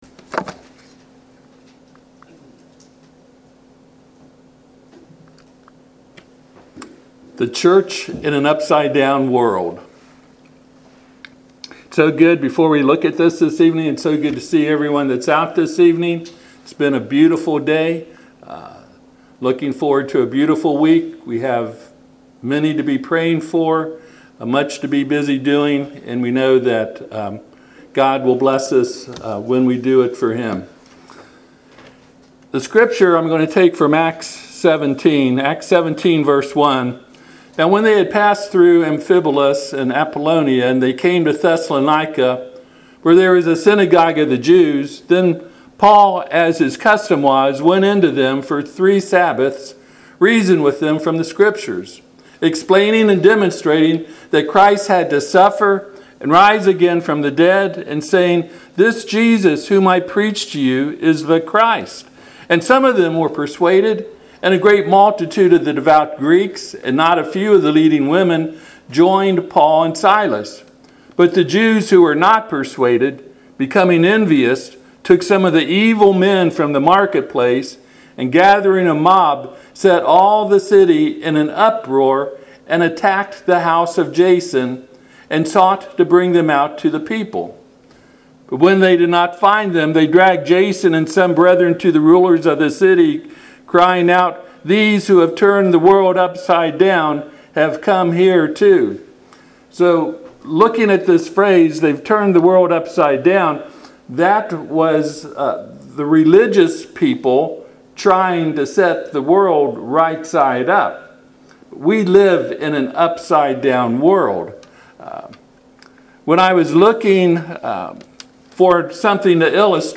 Passage: Acts 17:5-6 Service Type: Sunday PM